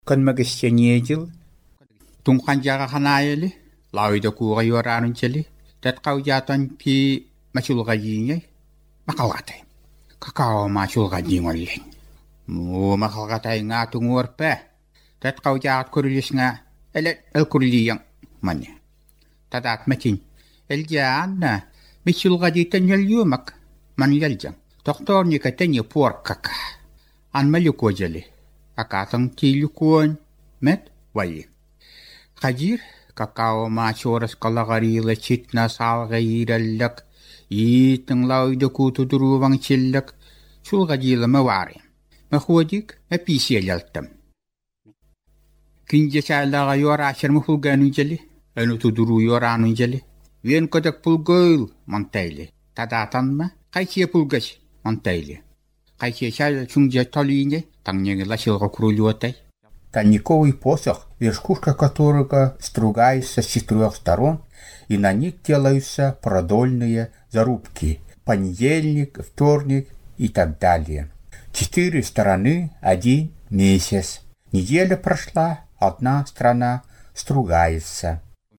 Тексты представляют собой естественные нарративы, записанные в текстовом виде и позже озвученные другим человеком (аудио к некоторым отрывкам прилагаются).
Озвучка текстов 1 (1[0])